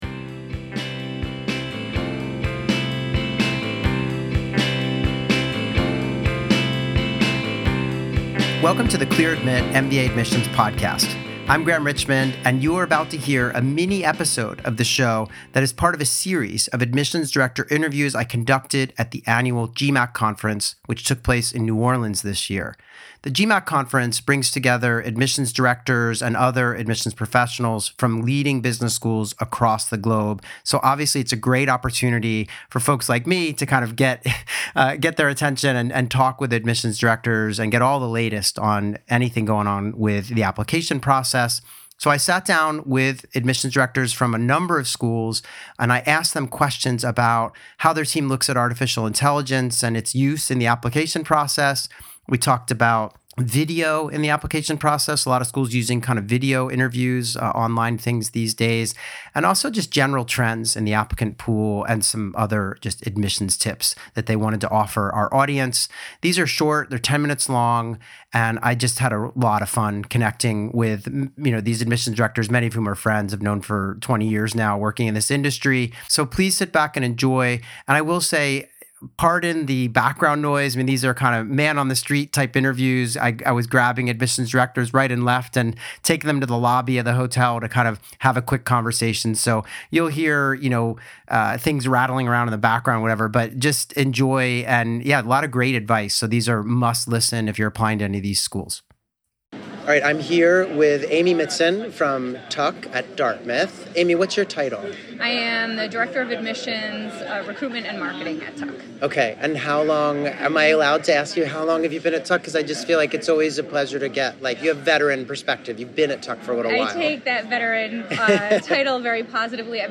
Tune into this special episode of the Clear Admit MBA Admissions Podcast, recorded live at the 2024 GMAC conference, to learn more about the Tuck School of Business at Dartmouth College.